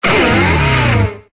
Cri d'Incisache dans Pokémon Épée et Bouclier.